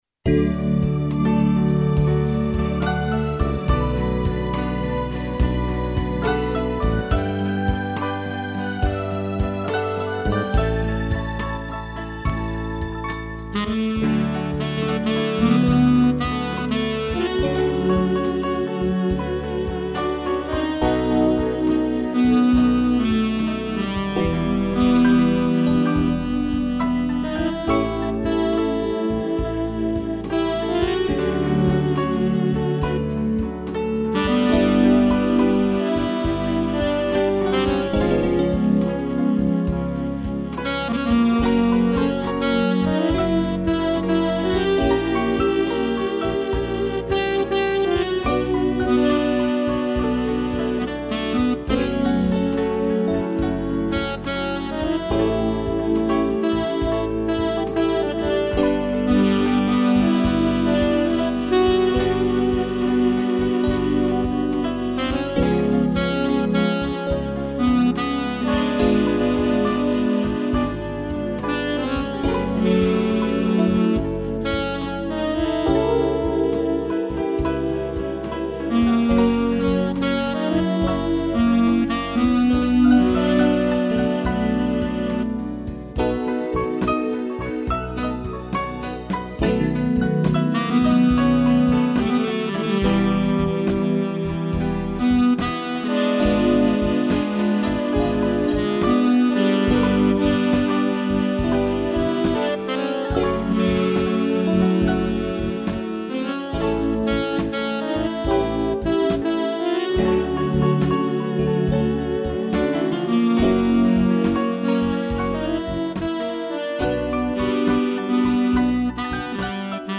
piano, sax e strings
(instrumental)